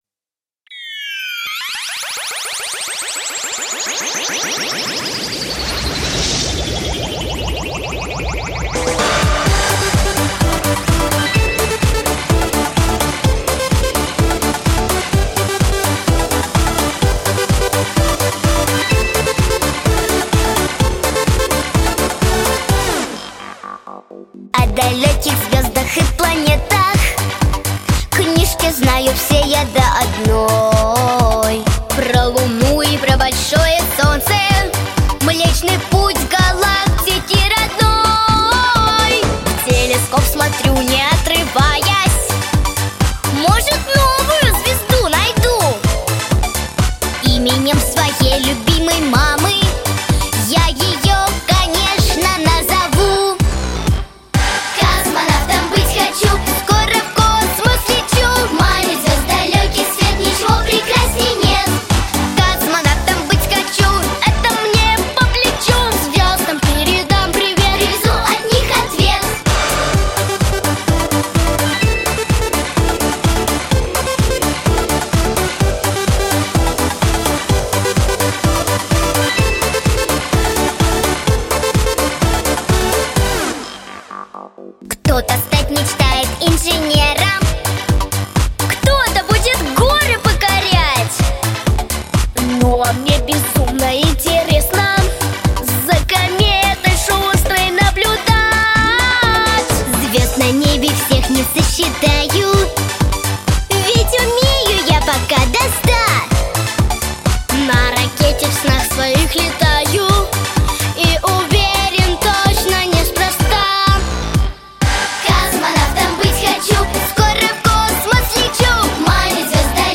• Жанр: Детские песни
Детская песня